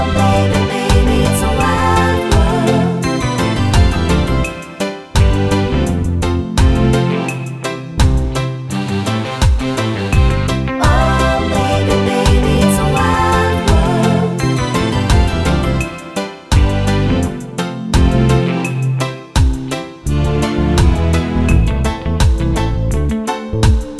no Backing Vocals Reggae 3:31 Buy £1.50